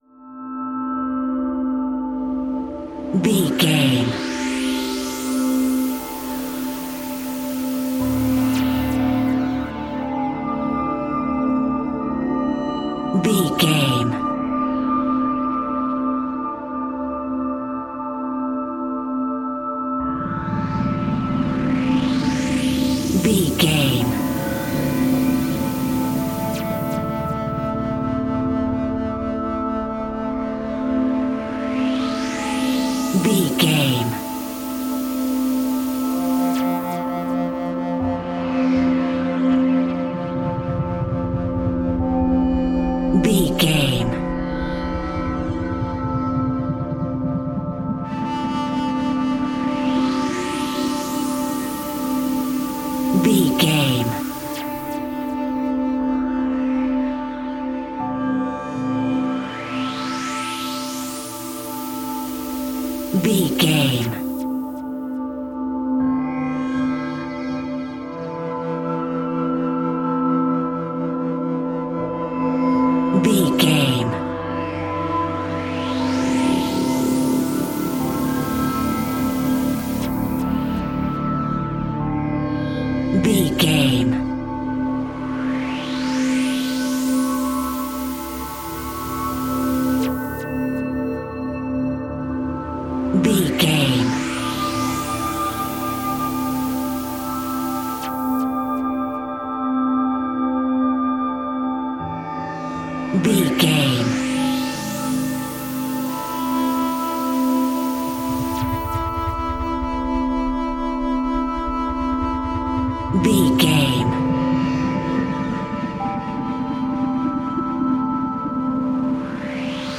Atonal
Slow
scary
tension
ominous
dark
suspense
eerie
piano
synthesiser
Horror synth
Horror Ambience
electronics